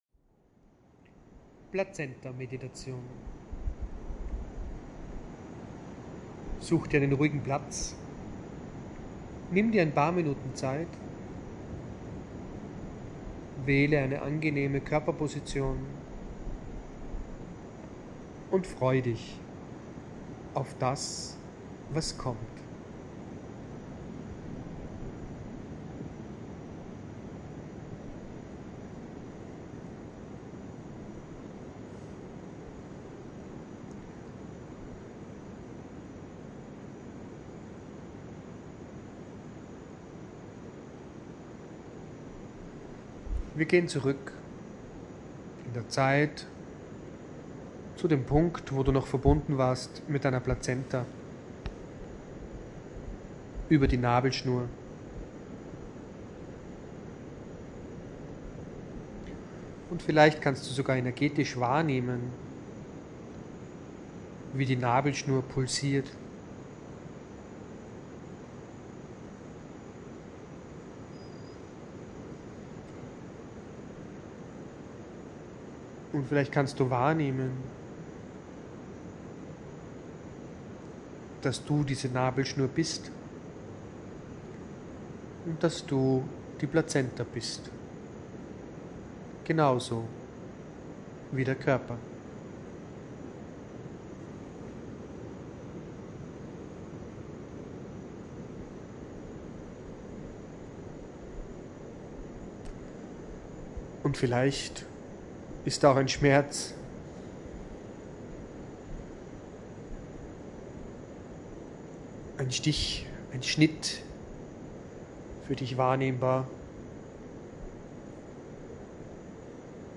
Premiummeditation - Plazenta-Meditation:
Plazentameditation.mp3